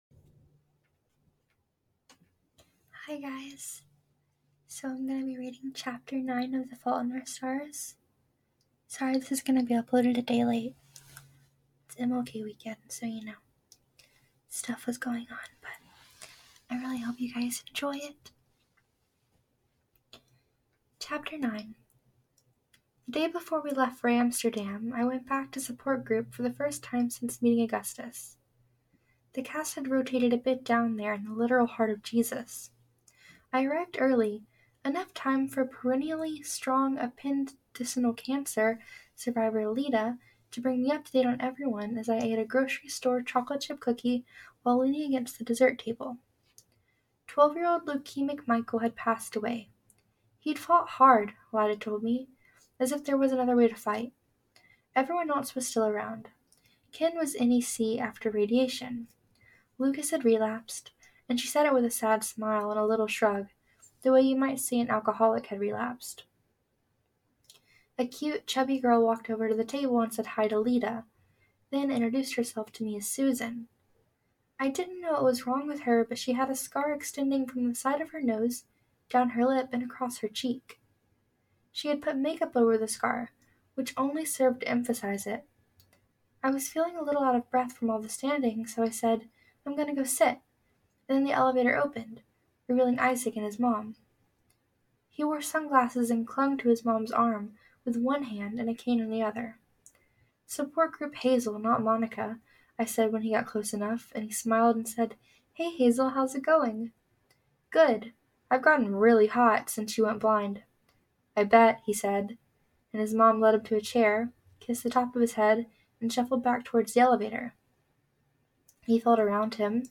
as always, no editing.. just me and my microphone and the book! :3